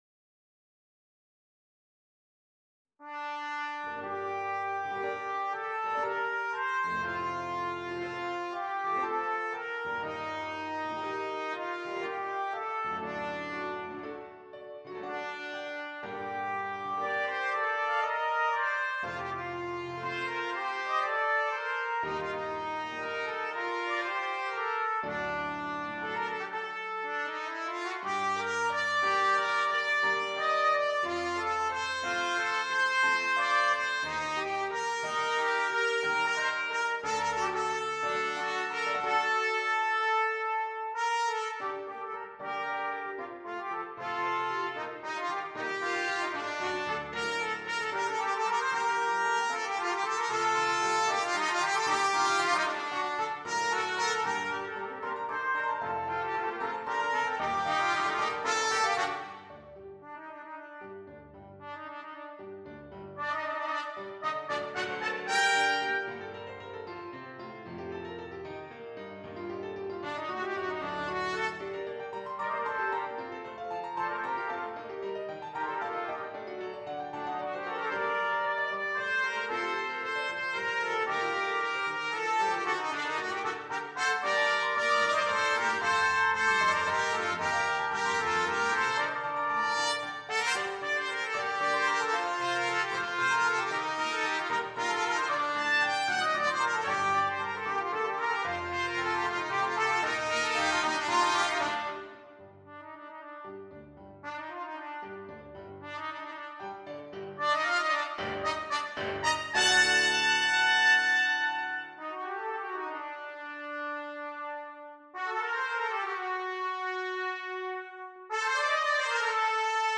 2 Trumpets and Keyboard